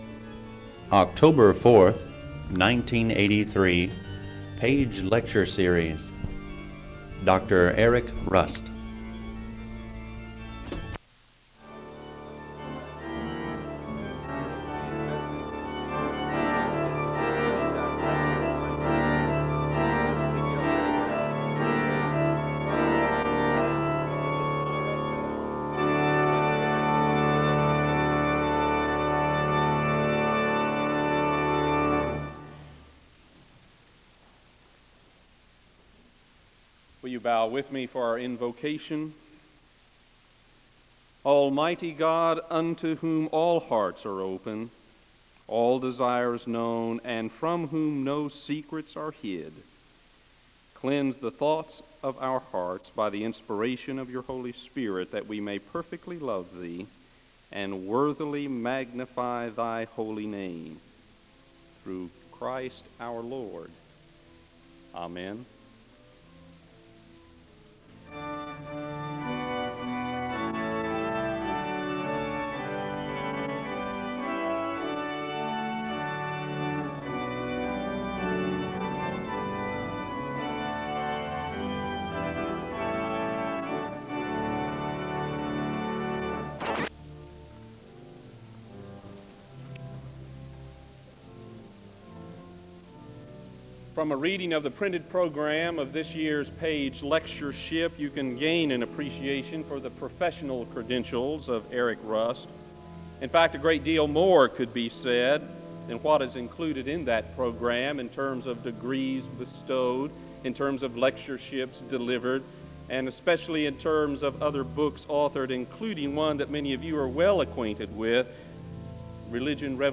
The service begins with organ music, and the speaker gives a word of prayer (00:00-01:05).
The service ends with a word of prayer (46:49-47:32).
SEBTS Chapel and Special Event Recordings